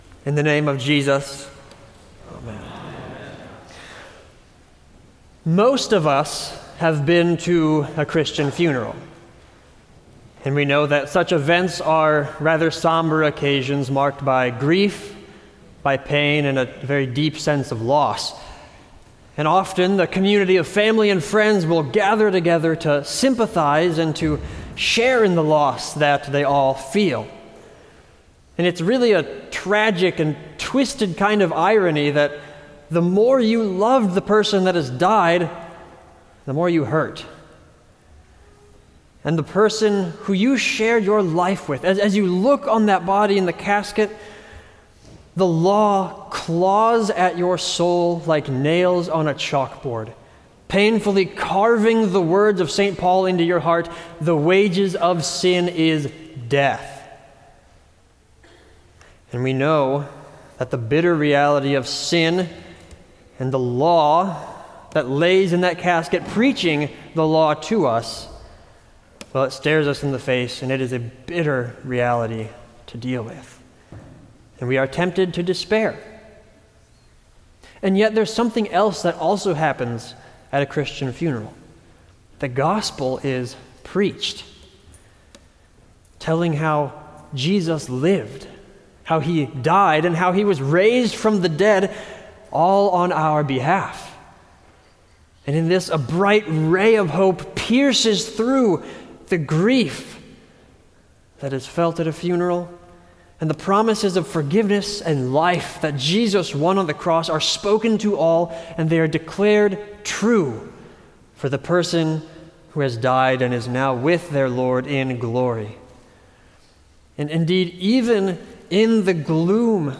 Sermon for All Saints